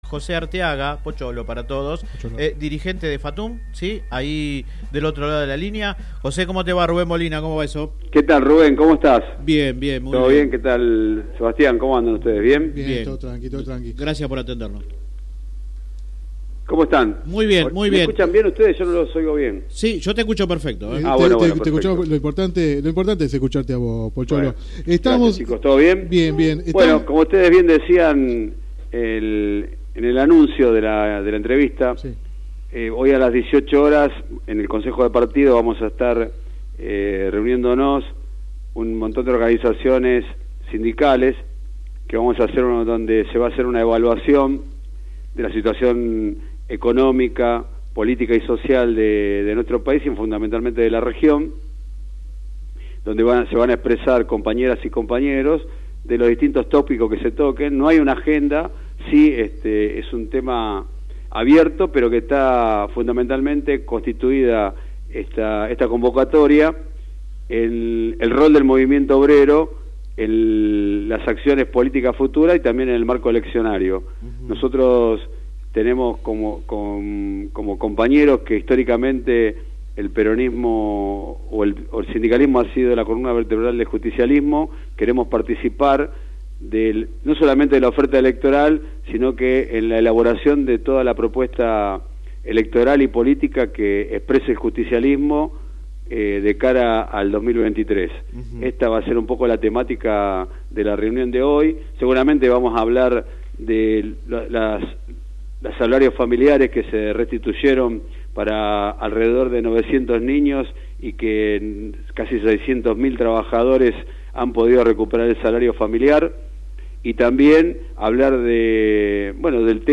El sindicalista habló en el programa radial Sin Retorno (lunes a viernes de 10 a 13 por GPS El Camino FM 90.7 y AM 1260).